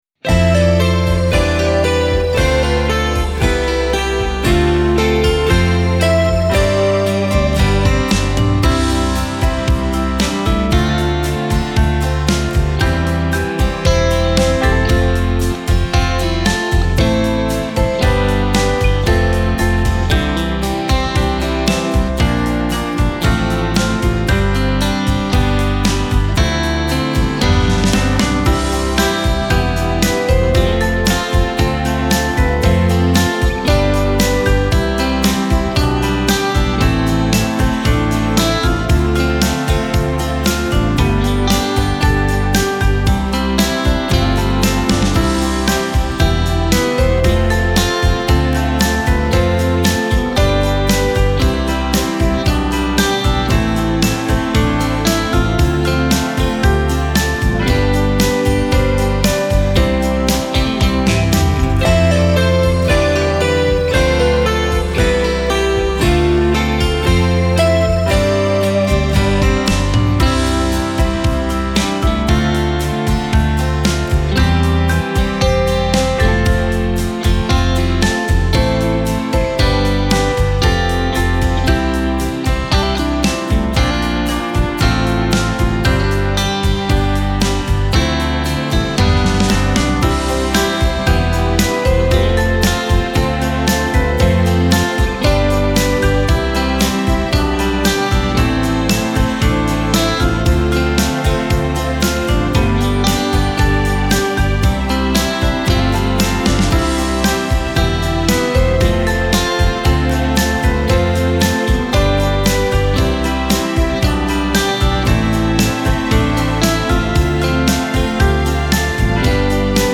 Lied